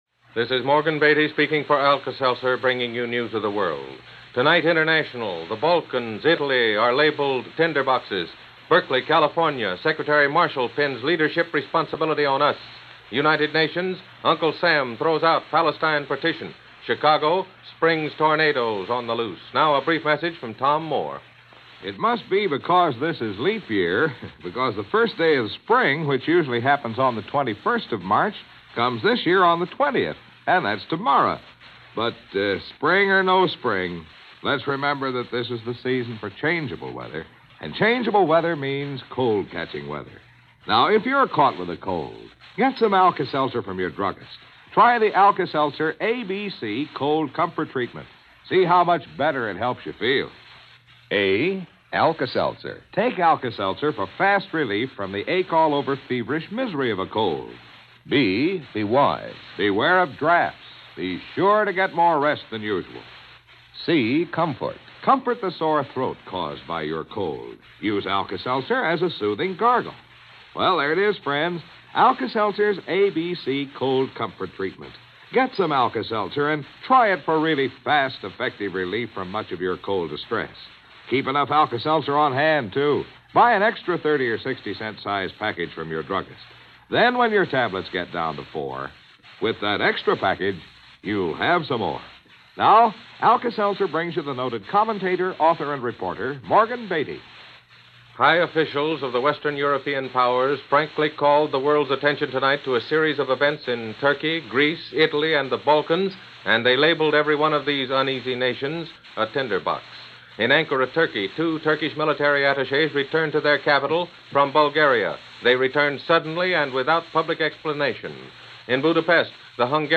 March 19, 1948 - A Cold War Tinderbox - Roman Spring - The Marshall Plan - news for this March 19, 1948 as presented by NBC Radio's News of The World.